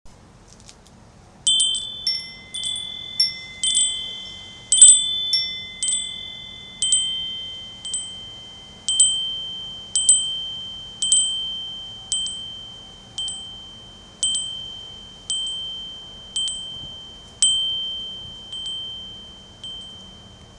windchime1
chime ding ringing wind windchime sound effect free sound royalty free Sound Effects